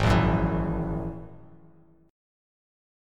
G#7sus2#5 chord